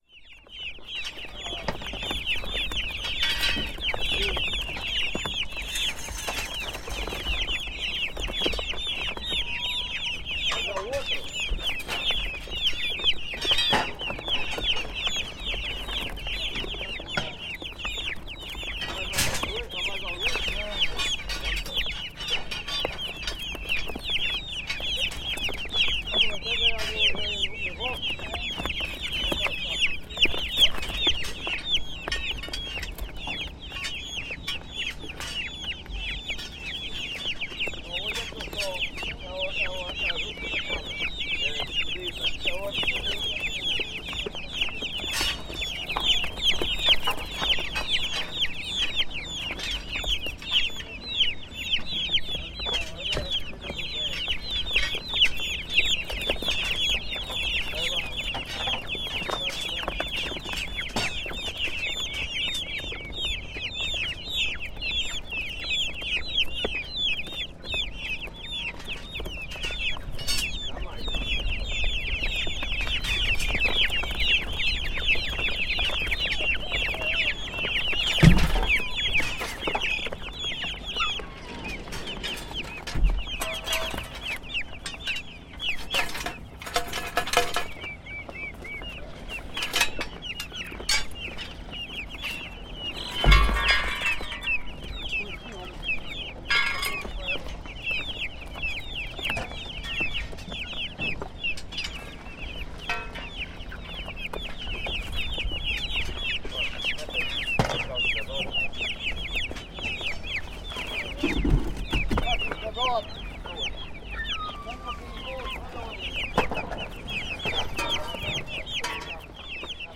Gravação do som de pintainhos enquanto um casal de vendedores de animais de criação desmontam a tenda de venda. Gravado com digital Zoom H4.
NODAR.00118 – Viseu: Largo da Feira – Vendedores de pintainhos desmontam tenda
Tipo de Prática: Paisagem Sonora Rural
Viseu-Largo-da-Feira-Vendedores-de-pintainhos-desmontam-tenda.mp3